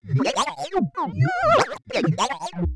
AFX_DROIDTALK_4_DFMG.WAV
Droid Talk 4